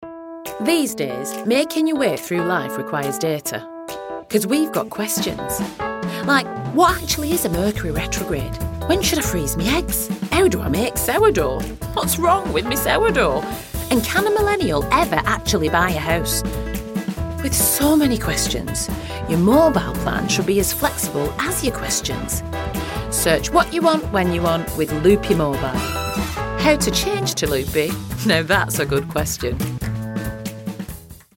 northern english | natural
Victoria_Ekanoye_NorthernEnglish.mp3